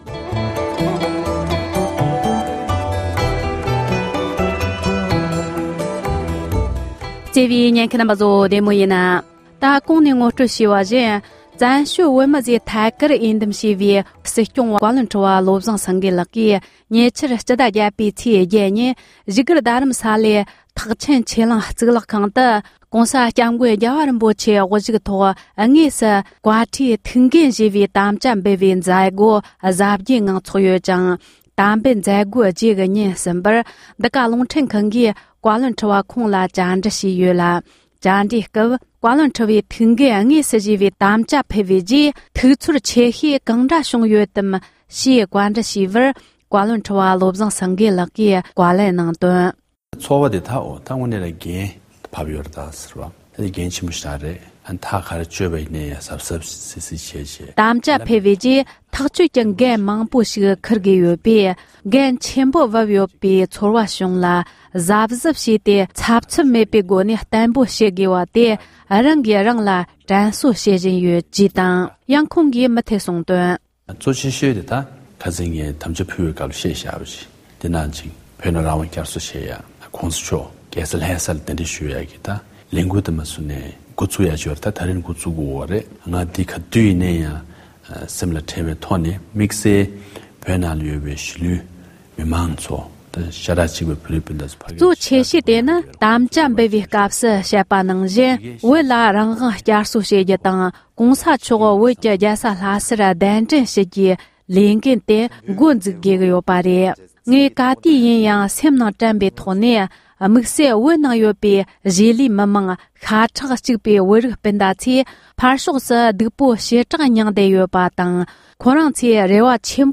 བཀའ་ཁྲི་བློ་བཟང་སེངྒེ་མཆོག་ལ་བཅར་འདྲི།